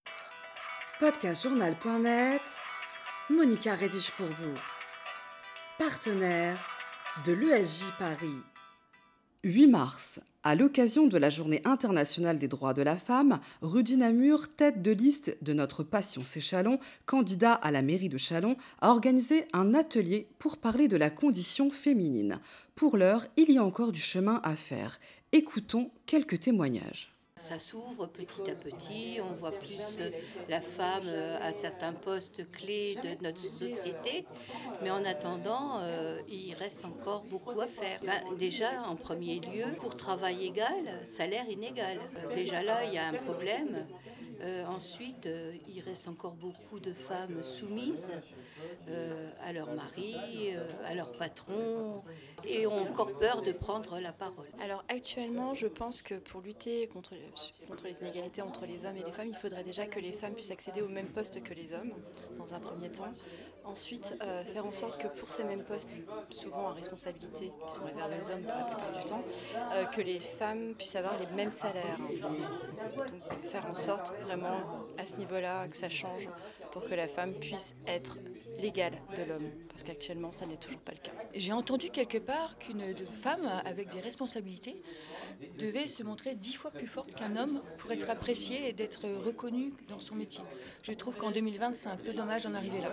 Témoignages à la suite du débat_Condition féminine à Châlons.wav (15.64 Mo)